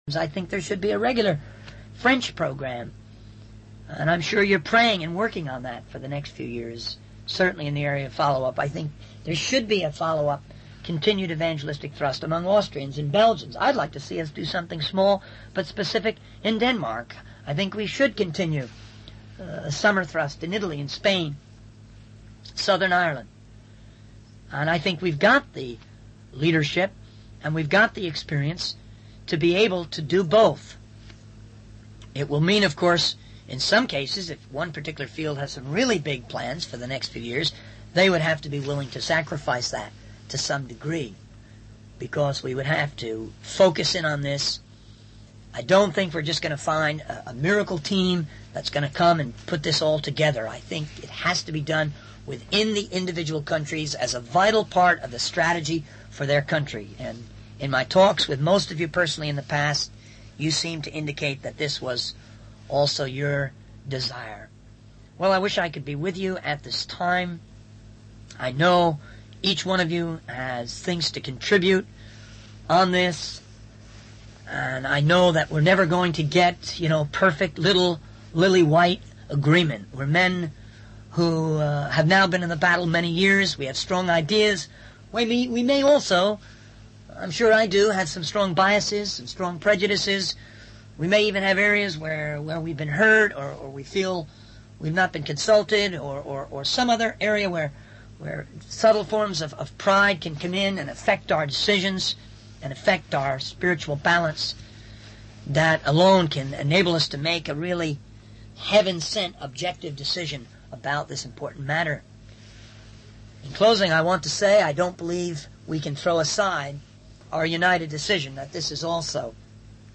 In this sermon, the speaker discusses the importance of the audiovisual department in spreading the message of God.